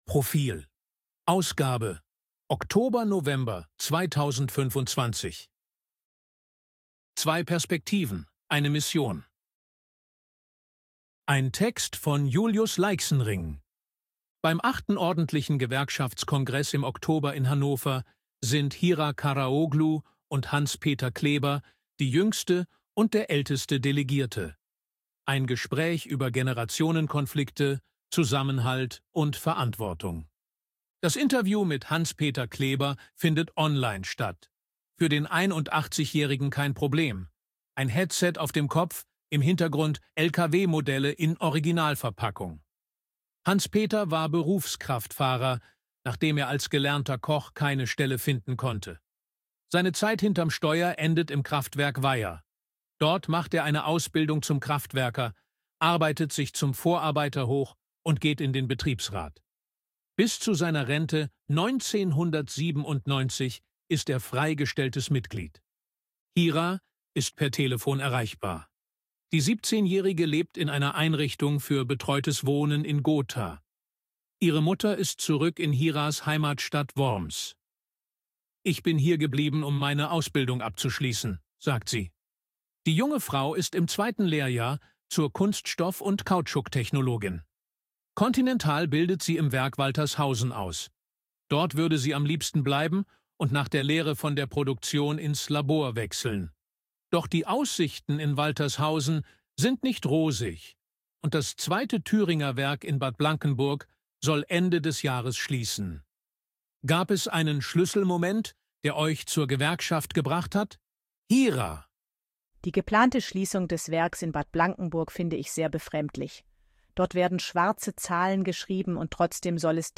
ElevenLabs_255_KI_Stimme_M-M-F_Portrait.ogg